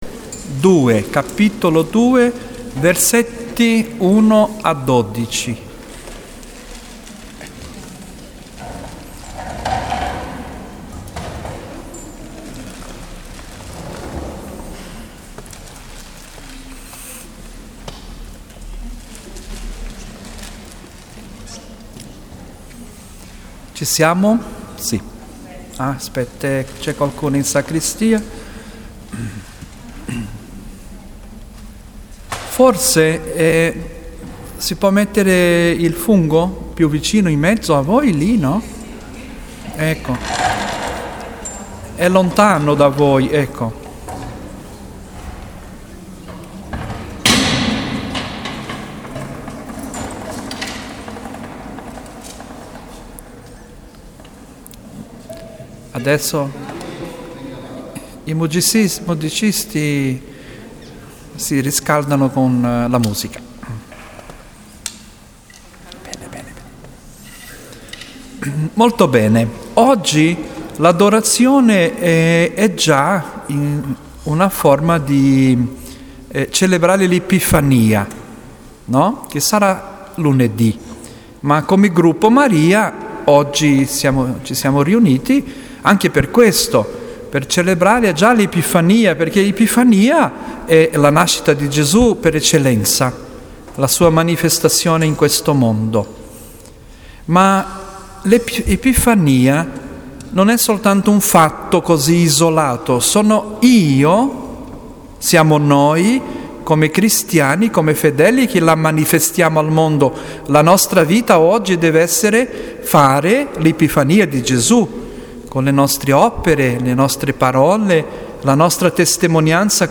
Riflessione introduttiva